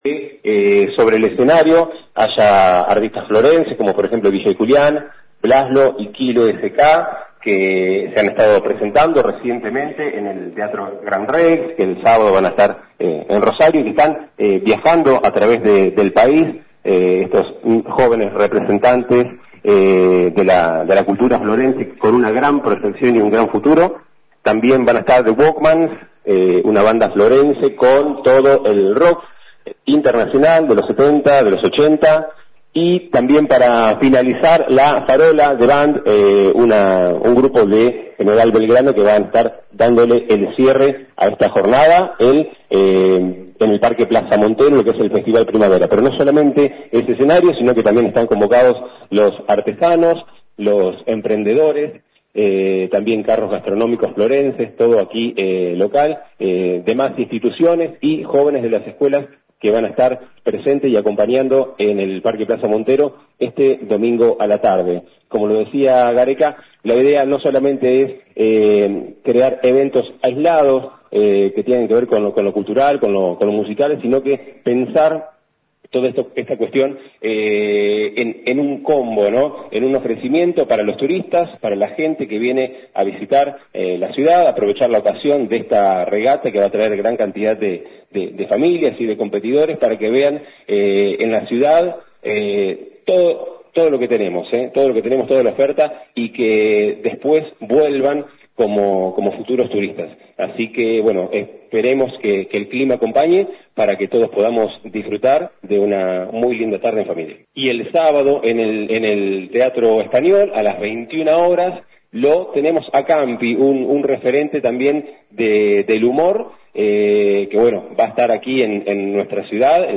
Conferencia-eventos.mp3